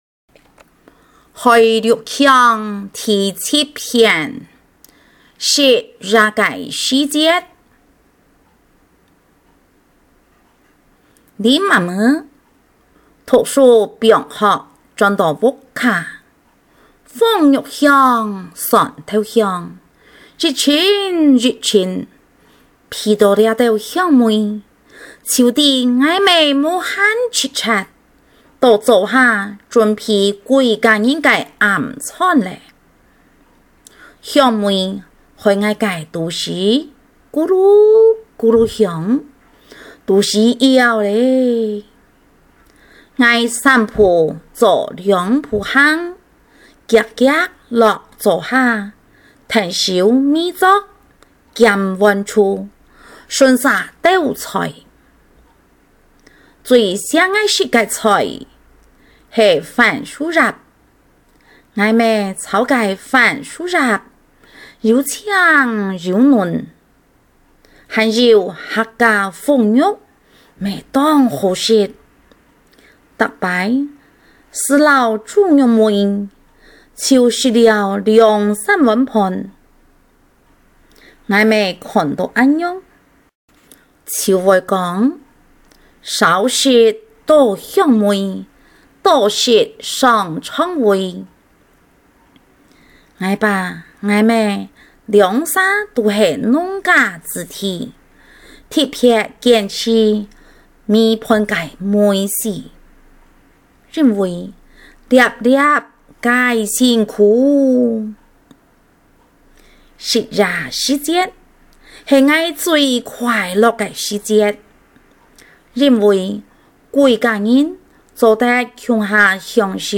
校內多語文競賽---公告客語朗讀(海陸腔)---篇目2篇& 朗誦示範Demo帶